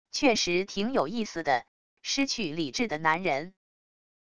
确实挺有意思的……失去理智的男人wav音频